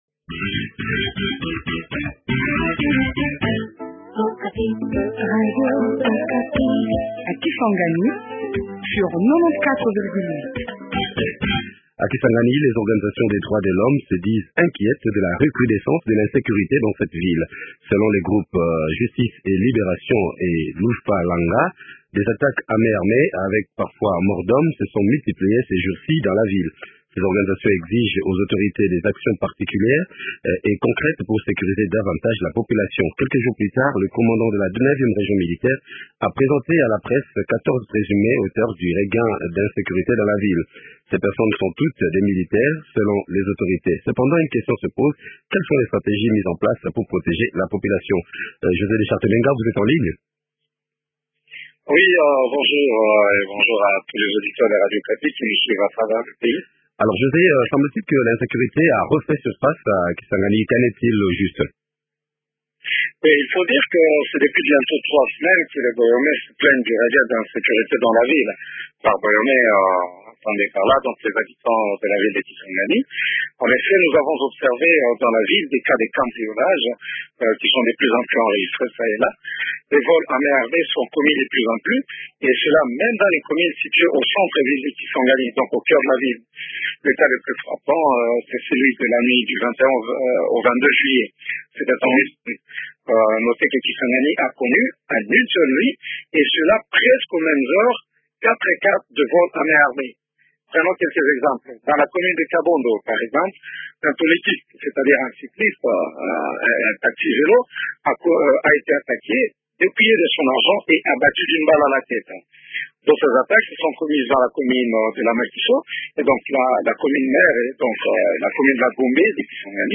Augustin Osumaka, maire de la ville de Kisangani.